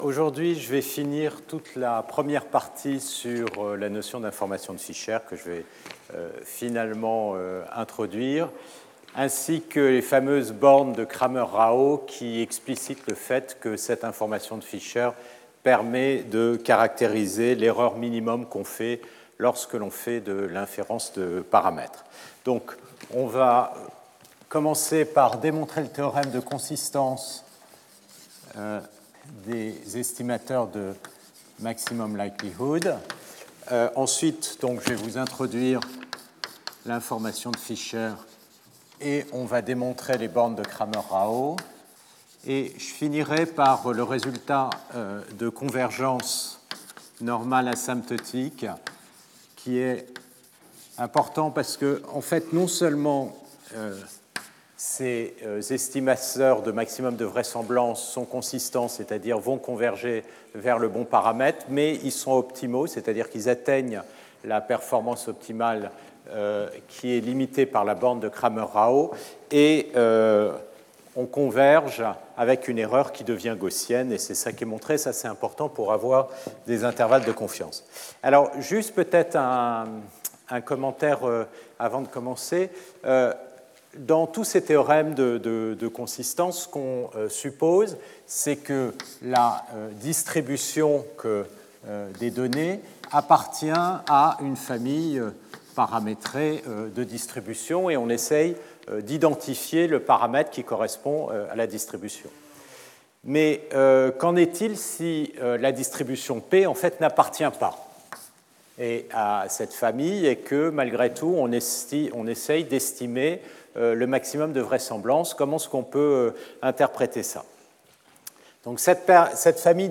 Stéphane Mallat Professeur du Collège de France